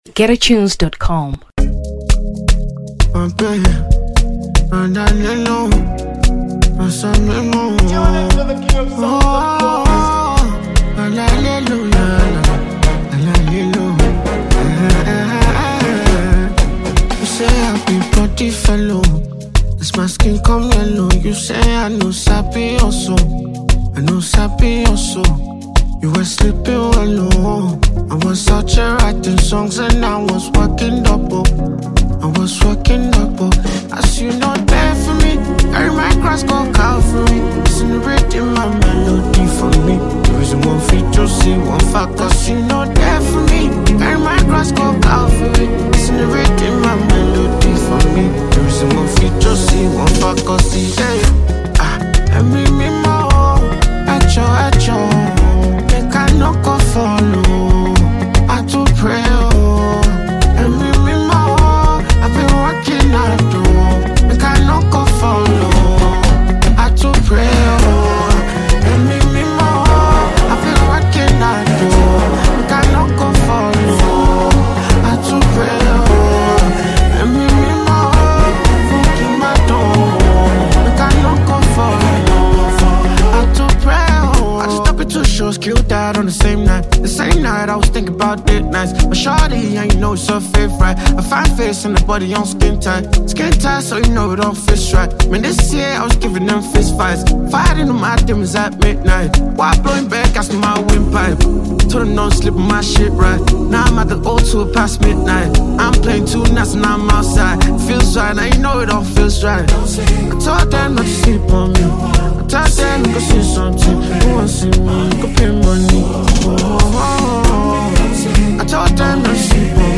Amapiano 2023 Nigeria